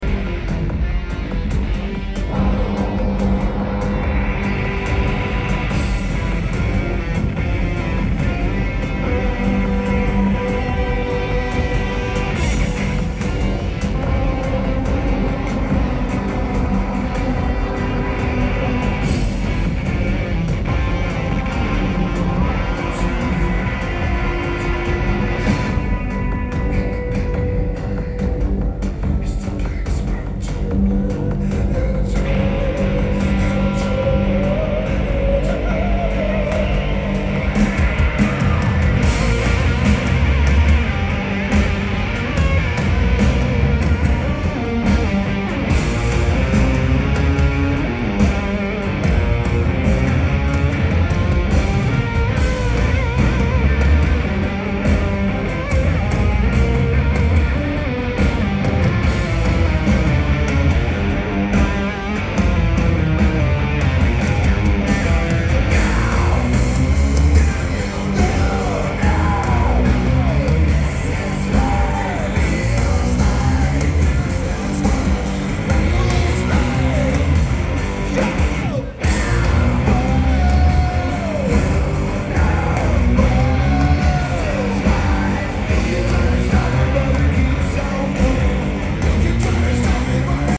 Starlight Amphitheater
Lineage: Audio - AUD (DPA 4061 + Batt. Box + R09HR)